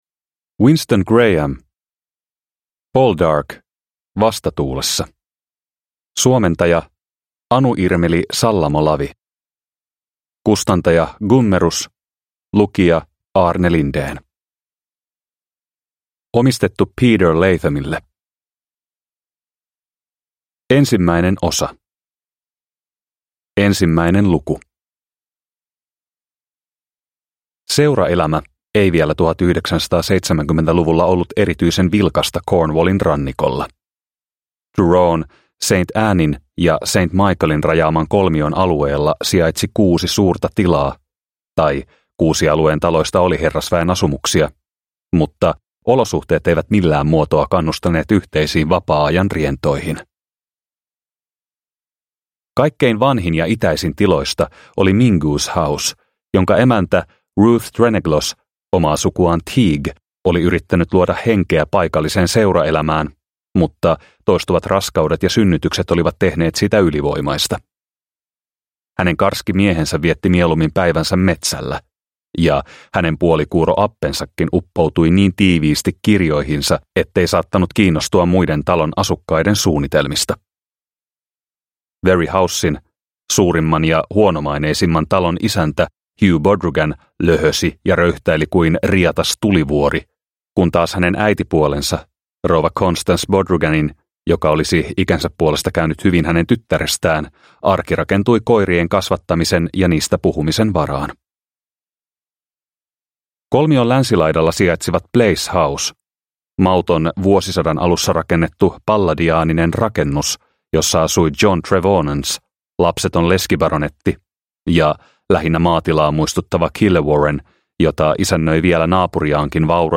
Poldark - Vastatuulessa – Ljudbok – Laddas ner